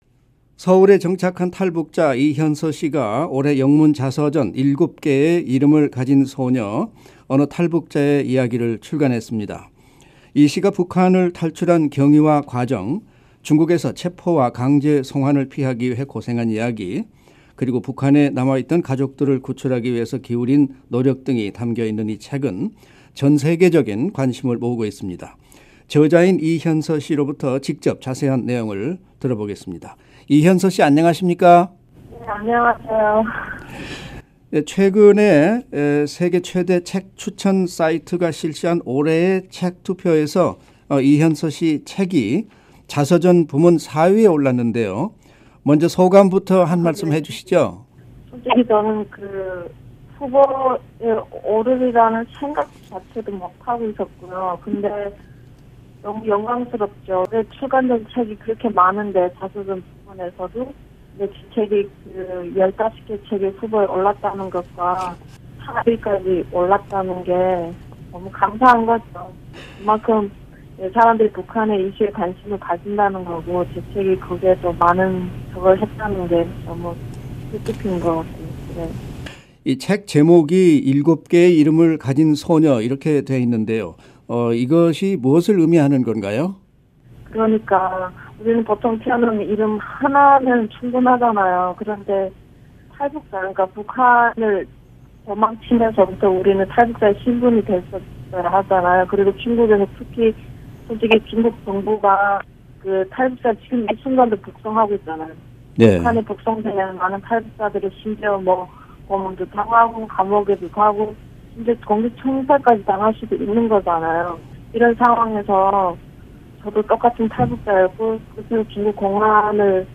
[인터뷰: 탈북자 이현서] 영문 자서전 '7개 이름을 가진 소녀' 관심